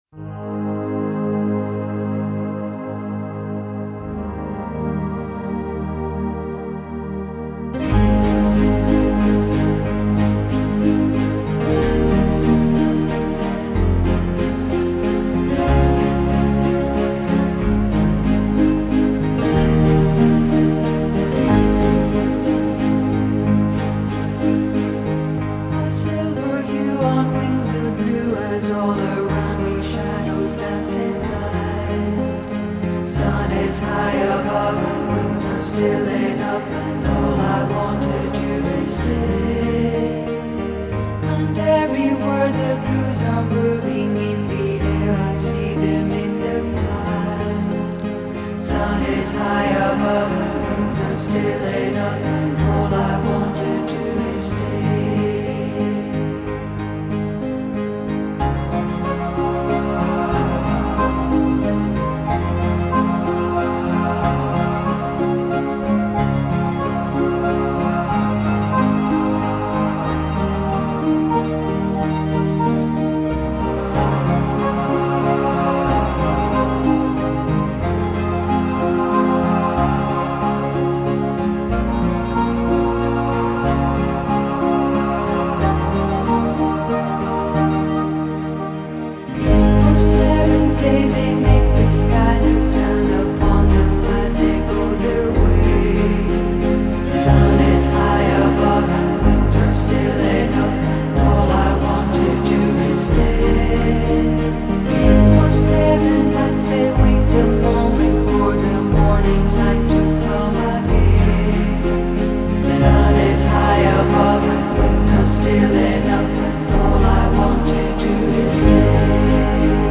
UK • Genre: New Age • Style: Celtic